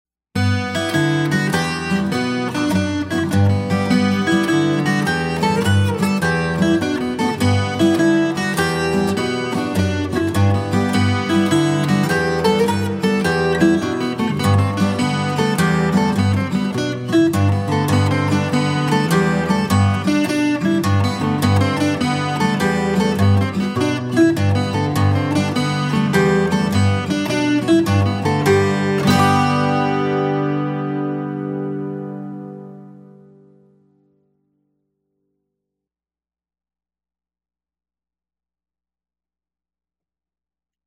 DIGITAL SHEET MUSIC - FLATPICK/PLECTRUM GUITAR SOLO
Irish Jig